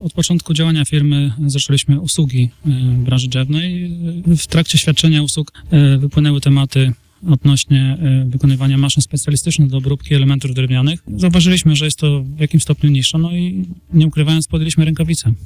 – mówił na naszej antenie